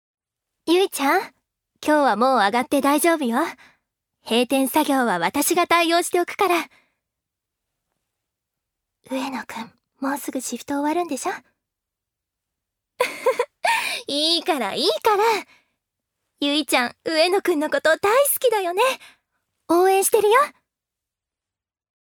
女性タレント
音声サンプル
セリフ４